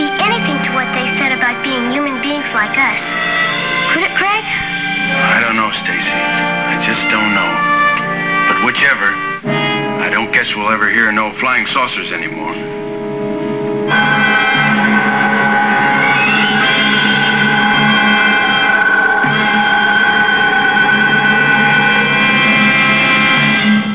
Jupiter 2 leaving Earth of 1947